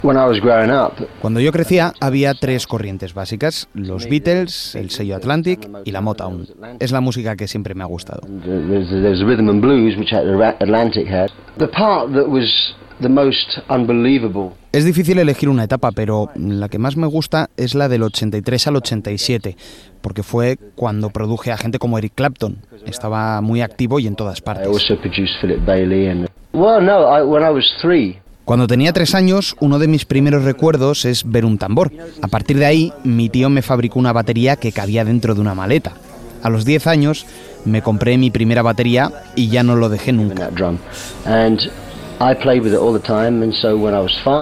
Respostes del músic Phil Collins a diverses preguntes, sobre la seva trajectòria, en la seva visita a Madrid per presentar el disc recopilatori "Hits"
FM